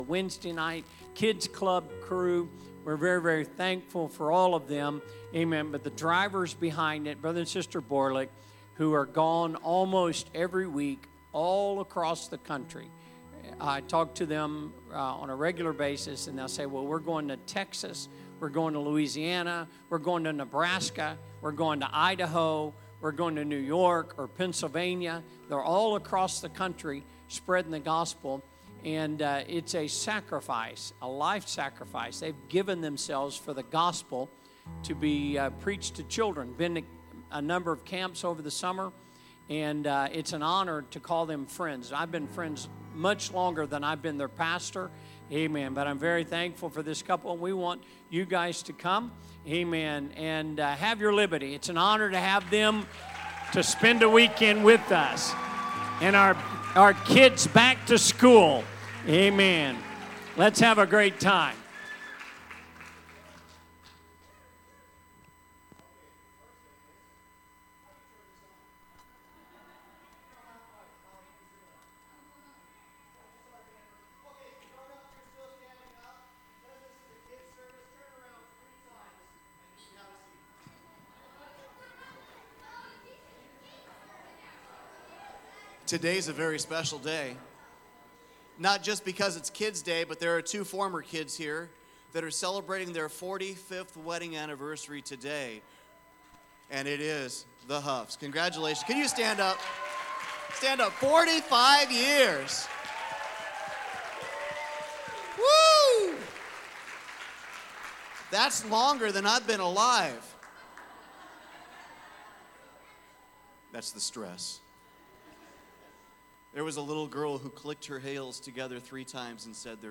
Sermons | Elkhart Life Church
Sunday Service - Part 3